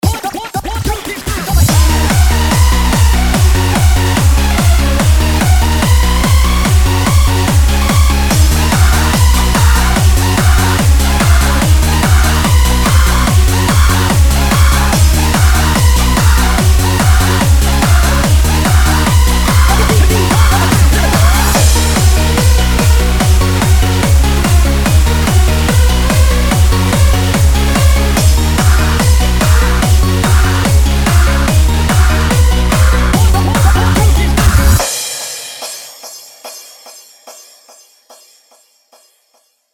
145 bpm
techno trance handsup oldschool remix
rave dance short experimental